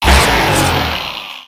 Audio / SE / Cries / EXCADRILL.ogg